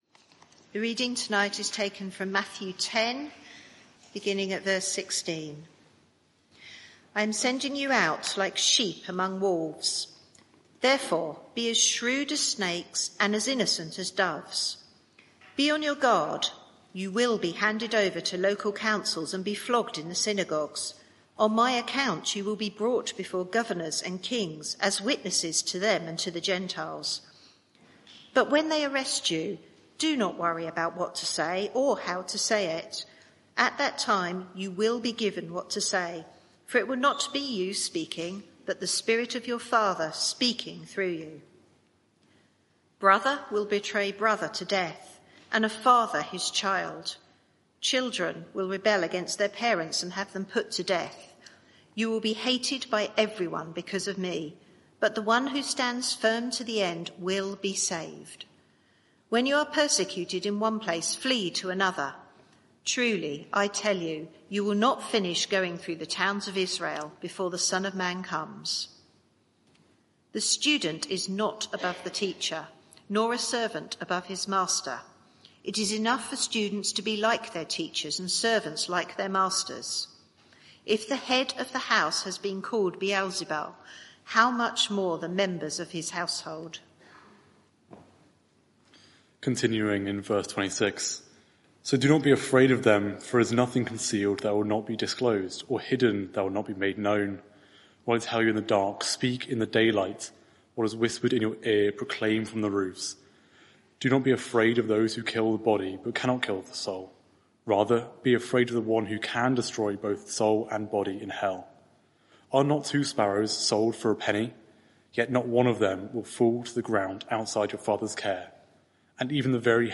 Media for 6:30pm Service on Sun 02nd Jun 2024 18:30 Speaker
Series: Jesus confronts the world Theme: Jesus' divisiveness Sermon (audio)